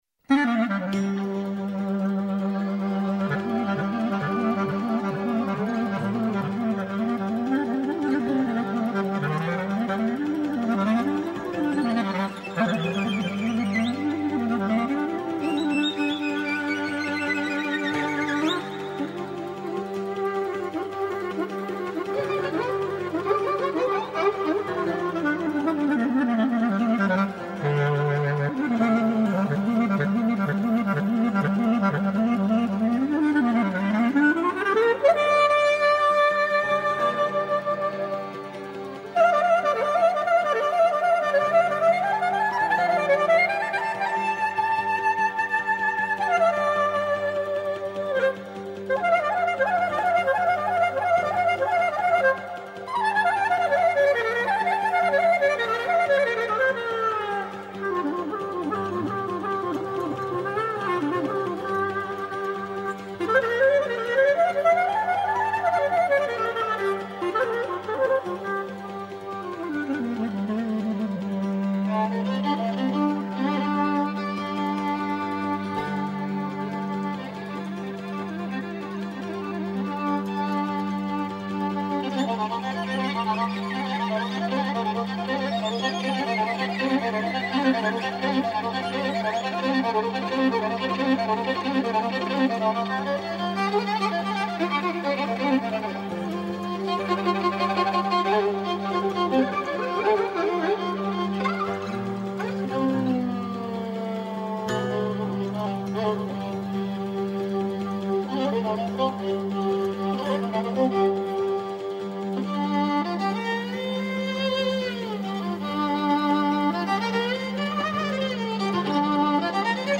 Ένα καθημερινό μουσικό ταξίδι στην παράδοση της Ελλάδας. Παλιές ηχογραφήσεις από το αρχείο της Ελληνικής Ραδιοφωνίας, νέες κυκλοφορίες δίσκων καθώς και νέες ηχογραφήσεις στο Στούντιο των Μουσικών Συνόλων της ΕΡΤ.